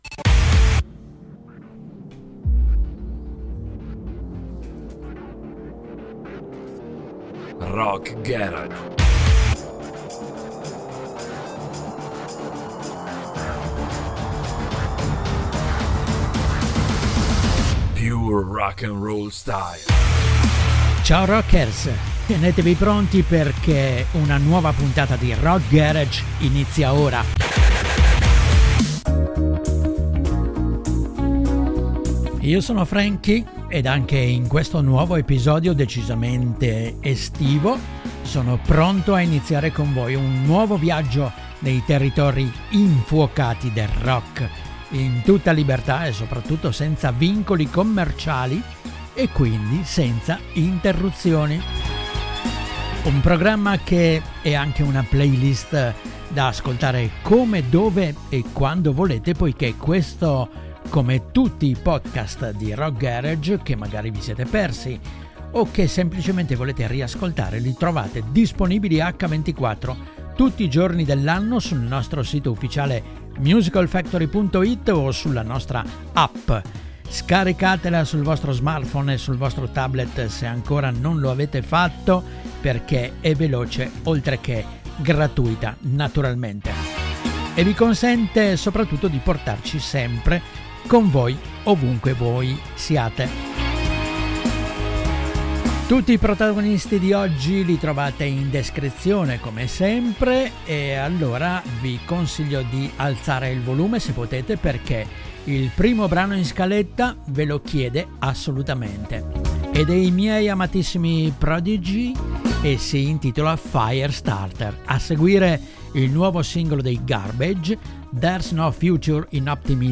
per vivere un’estate in puro stile rock’n’roll con